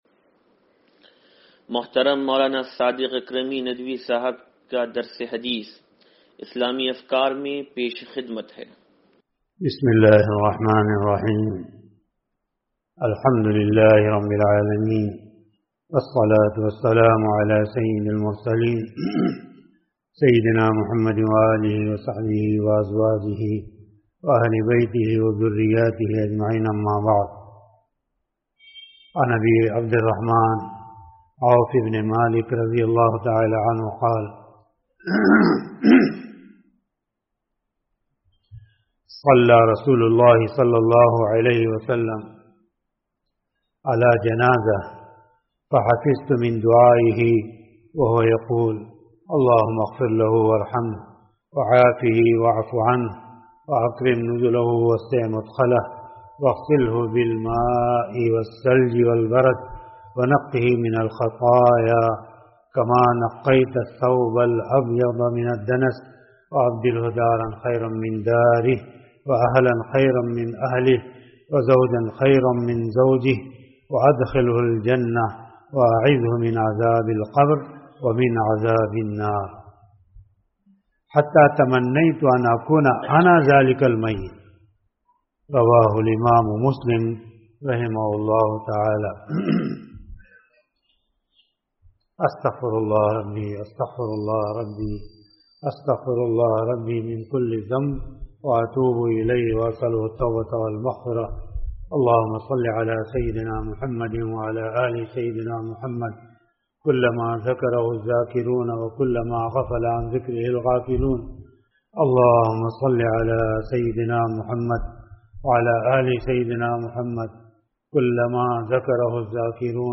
درس حدیث نمبر 0736